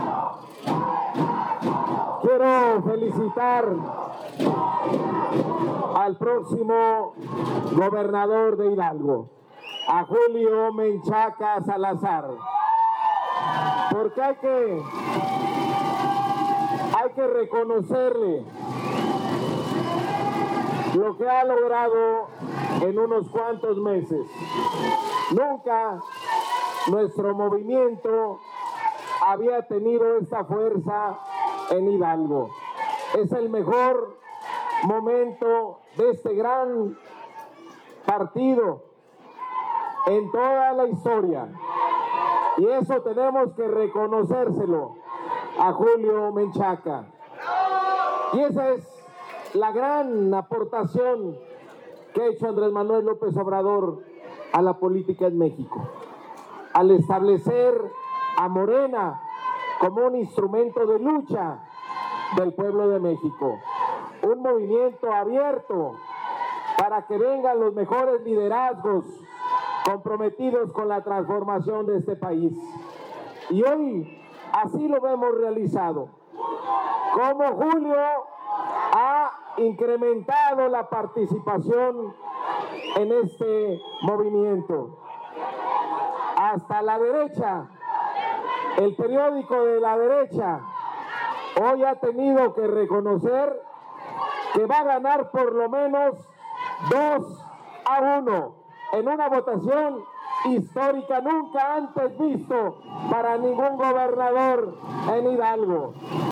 En su oportunidad, Mario Delgado, dirigente nacional de Morena, felicitó a Julio Menchaca por lo que ha logrado en unos cuantos meses: «Nunca nuestro movimiento había tenido tanta fuerza en Hidalgo. Morena es el instrumento de lucha del pueblo de México. Y es que la gente ya se cansó, ya despertó y por eso vamos a ganar».
Mario-Delgado_Tepehuacan_Chilijapa-1.mp3